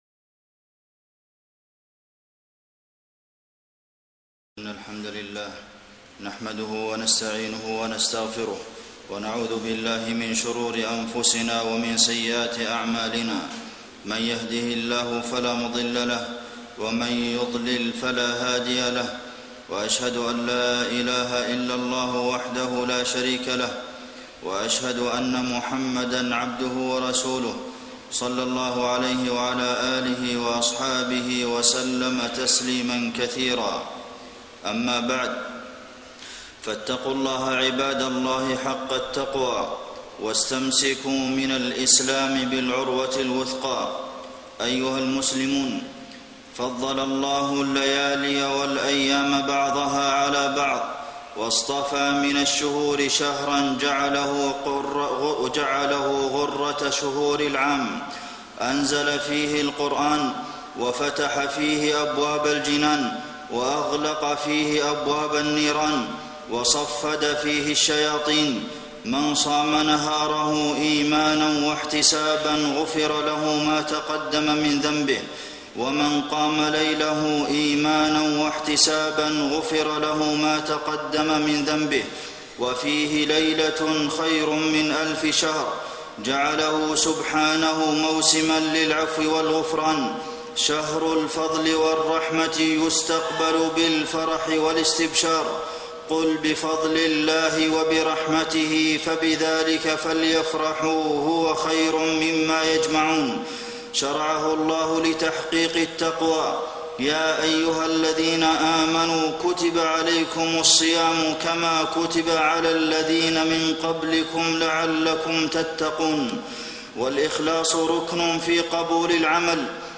خطبة الجمعة 3-9-1431هـ > خطب الحرم النبوي عام 1431 🕌 > خطب الحرم النبوي 🕌 > المزيد - تلاوات الحرمين